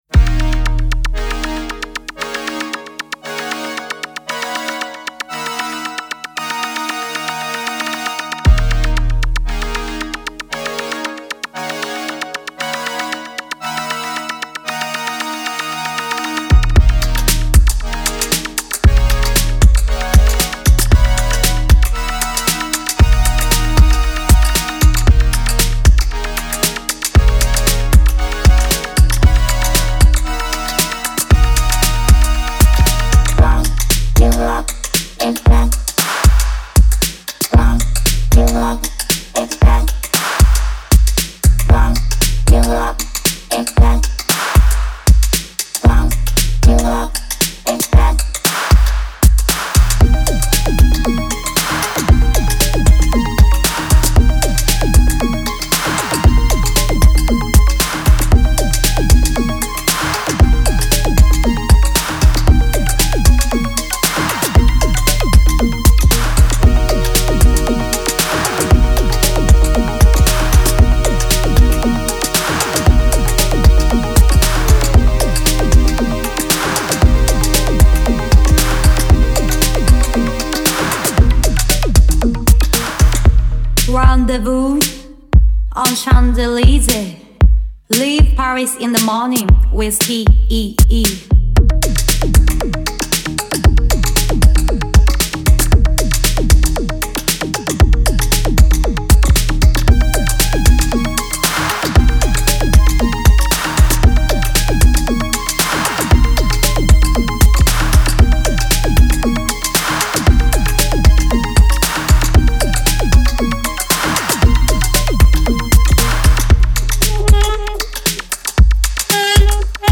ジャンル(スタイル) REGGAE / DISCO / JAPANESE